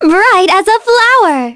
Cassandra-Vox_Skill1.wav